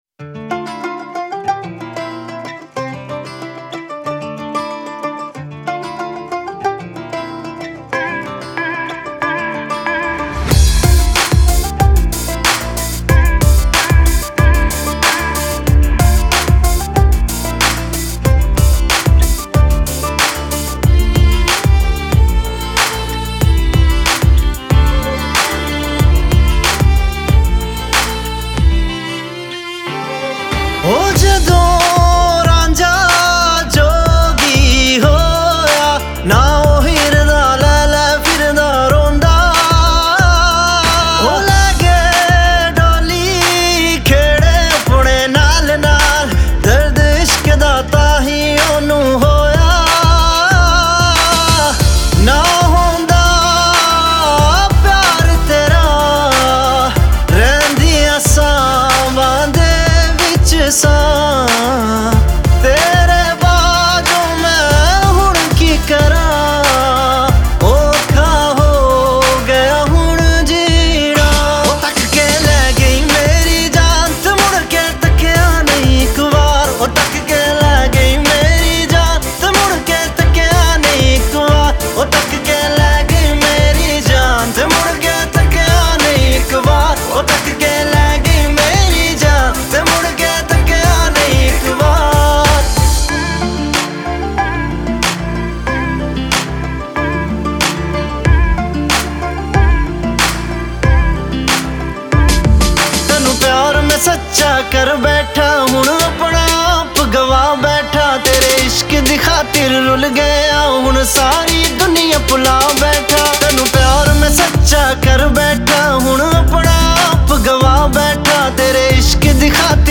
Punjabi DJ Remix Songs